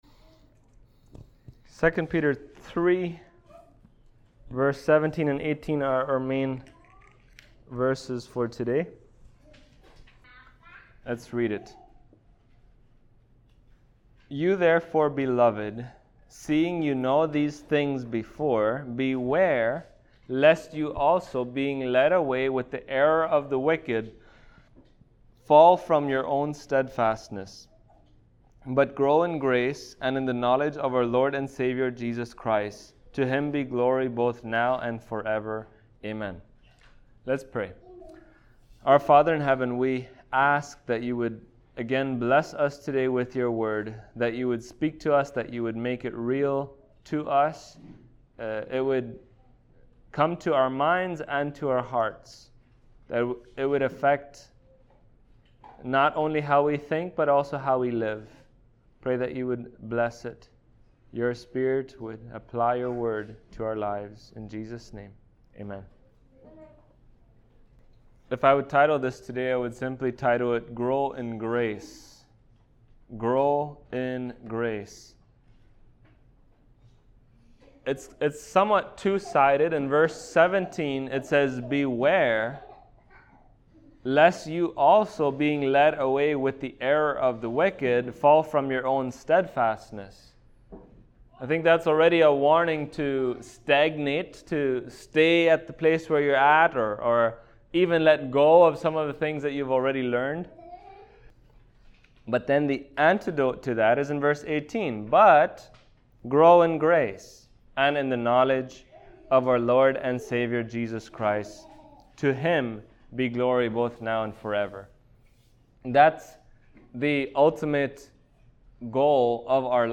1 Peter 3:17-18 Service Type: Sunday Morning Topics